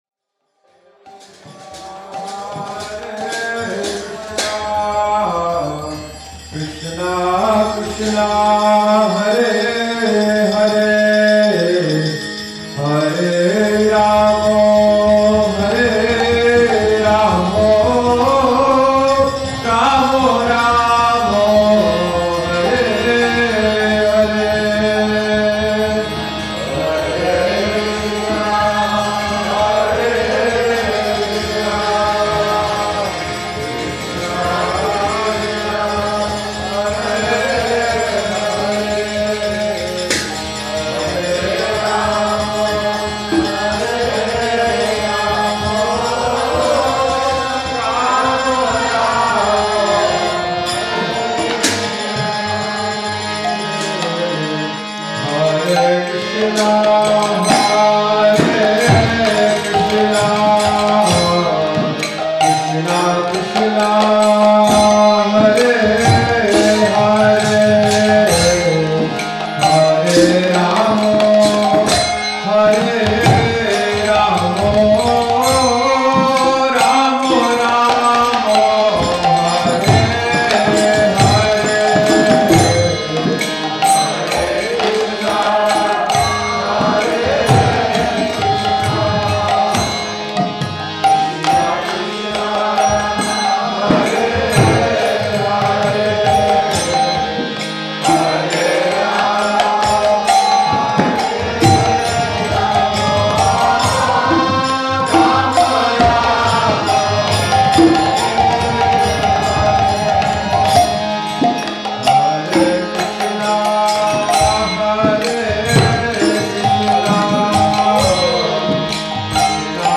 Bhajan – New Year’s Eve 2010